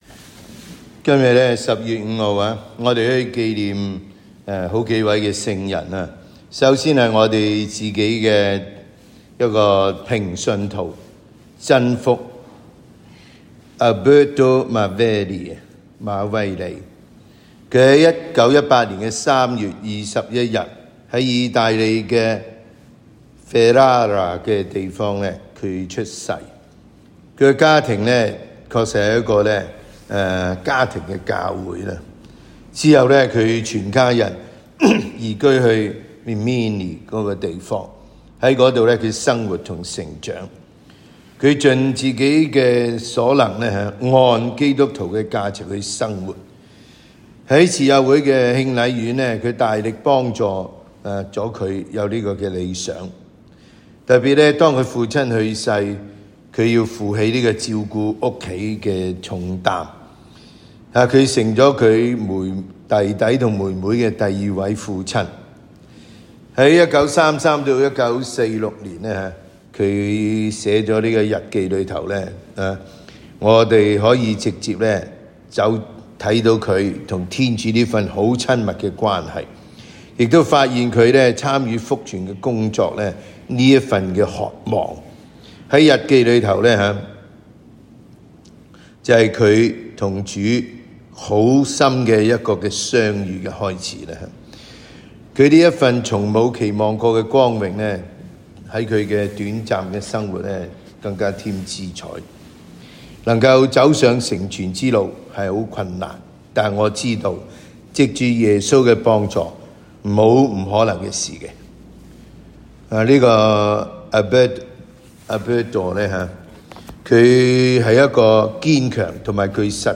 SDB 每日講道及靈修講座